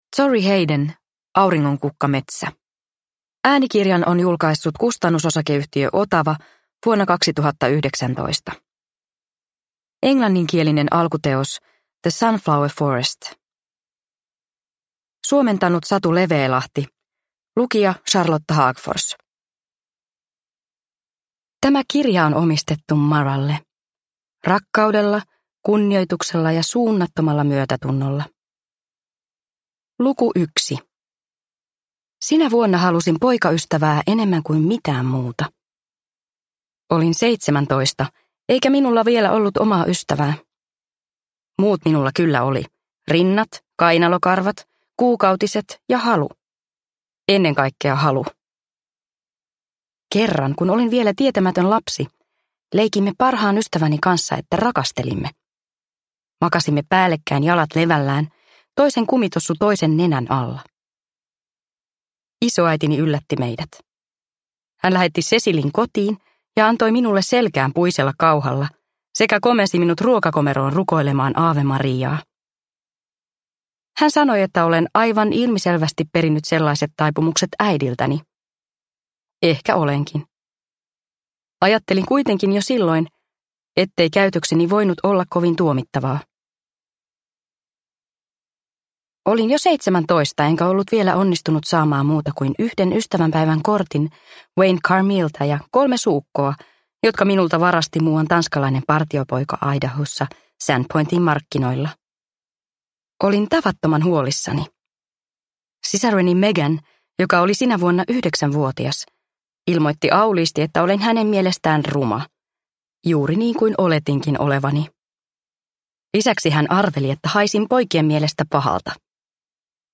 Auringonkukkametsä – Ljudbok – Laddas ner